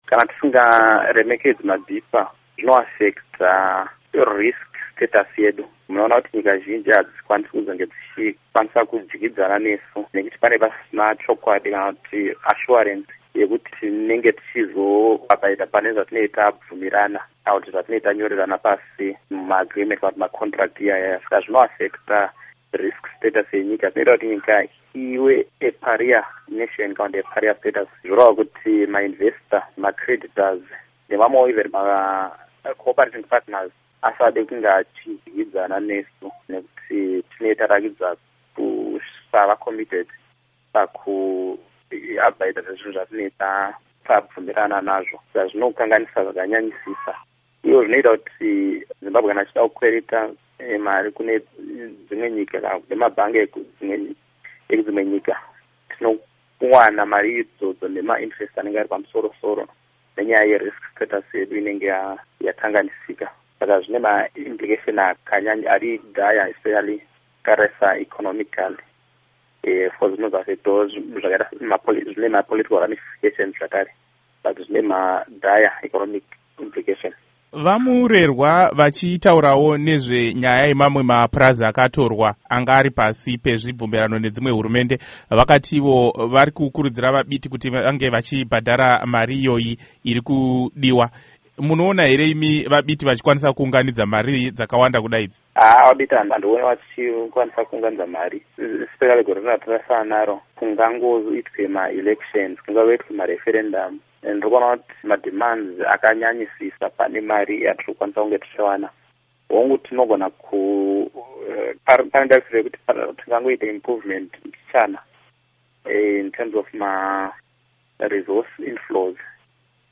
Hurukuro naVaJohannes Tomana